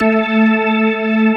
Index of /90_sSampleCDs/AKAI S6000 CD-ROM - Volume 1/VOCAL_ORGAN/POWER_ORGAN
P-ORG2  A3-S.WAV